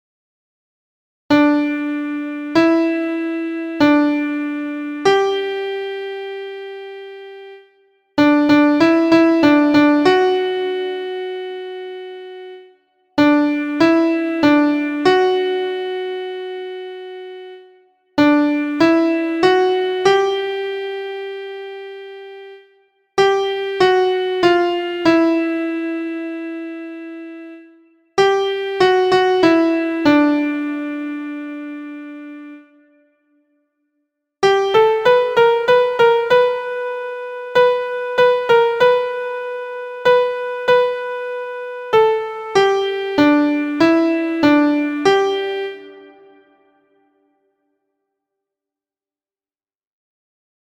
Repeated rest on the first beat, natural 7th (Te), and a sharped second (Ri) offer several new insights to music’s language.
• Origin: USA – Traditional Folk Song
• Key: G Major
• Time: 2/2 – (counted in 4/4)
• Form: through composed